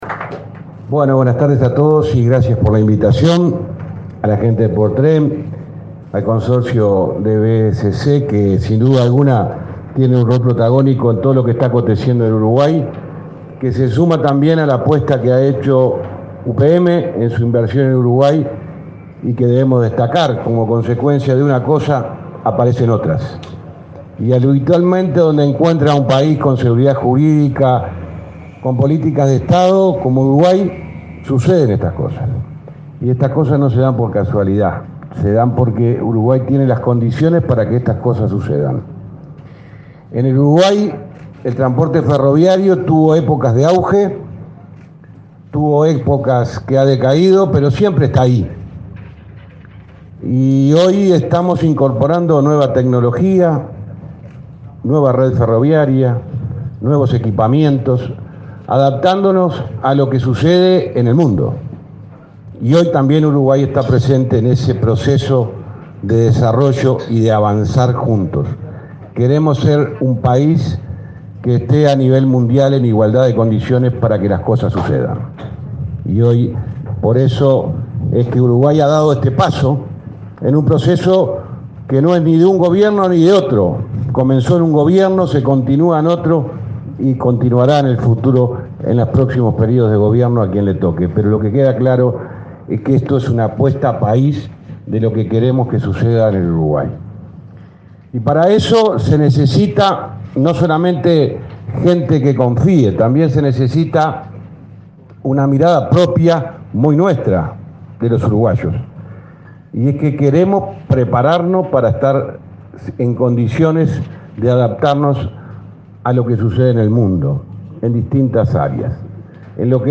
Palabras del ministro de Transporte, José Luis Falero
El ministro de Transporte, José Luis Falero, participó este miércoles 16, del acto de inicio de obras del Centro de Operación y Mantenimiento, que